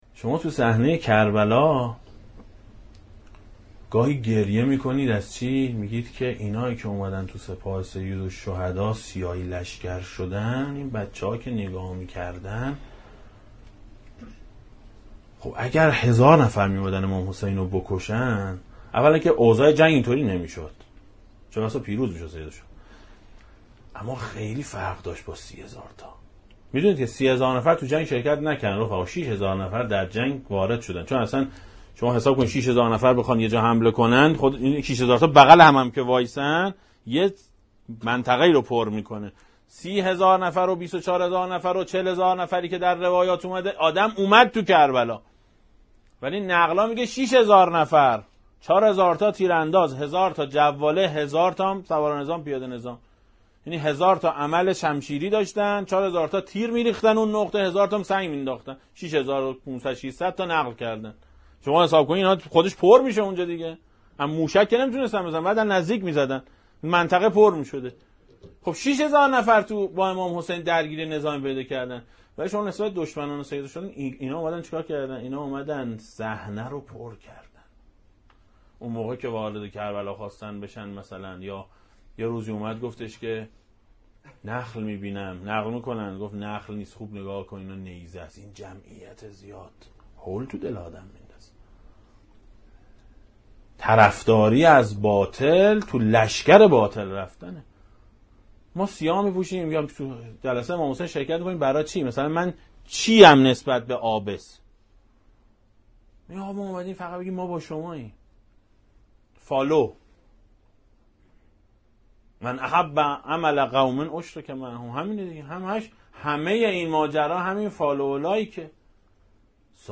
دسته: امام حسین علیه السلام, روضه های اهل بیت علیهم السلام, سخنرانی ها